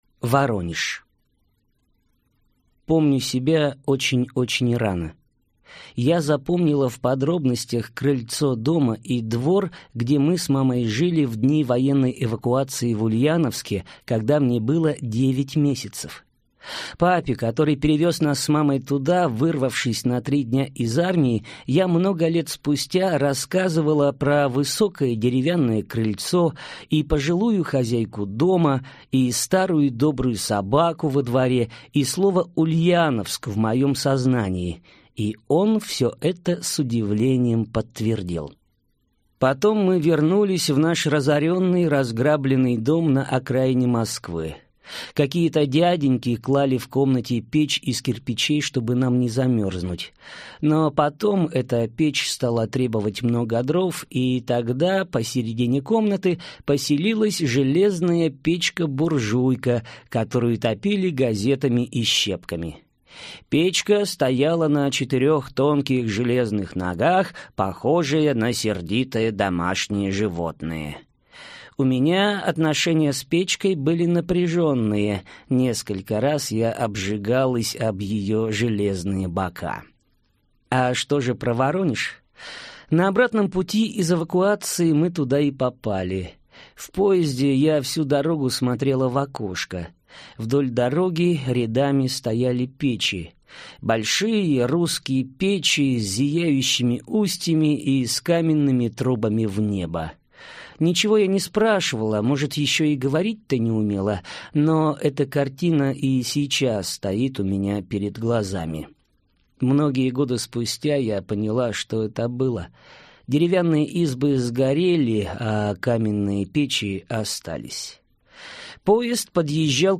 Аудиокнига Белый карандаш. И другие короткие истории | Библиотека аудиокниг